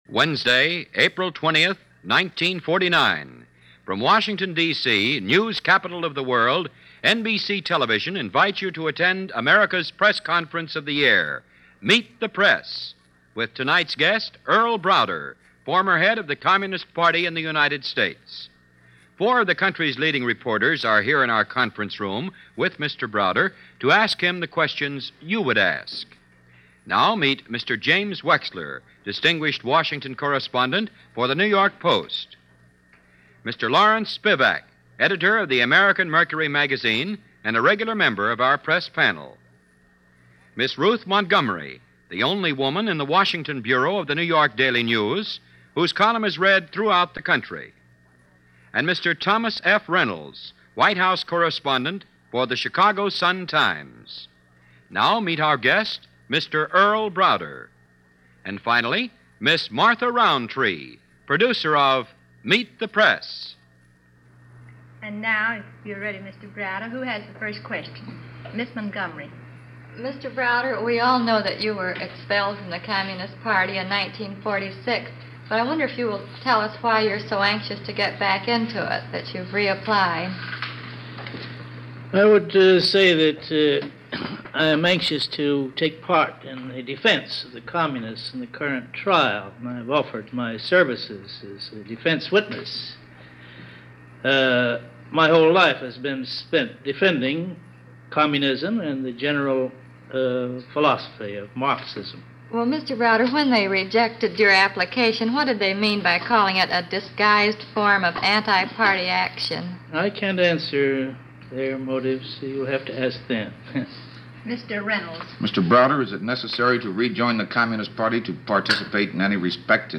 Earl Browder, former head of the Communist Party in America, is interviewed on Meet The Press, from April 20, 1949.